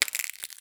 STEPS Glass, Walk 05.wav